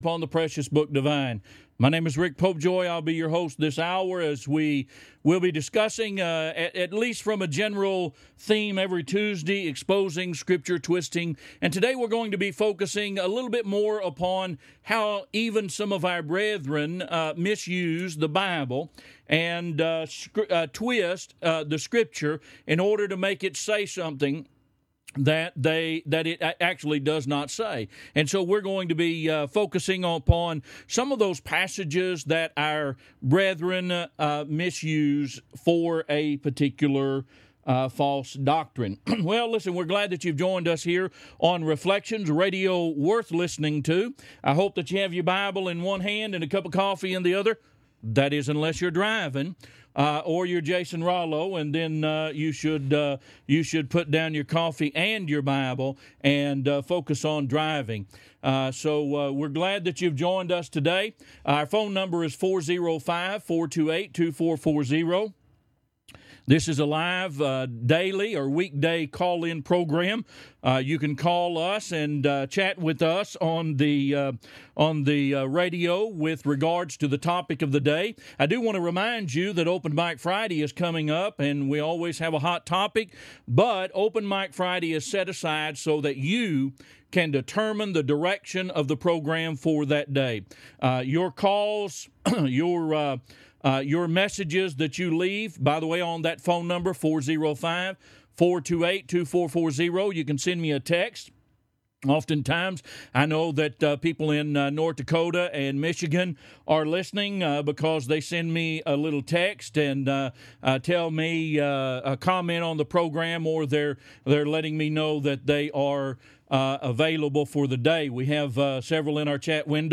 Program Info: Live program from the Nesbit church of Christ in Nesbit, MS.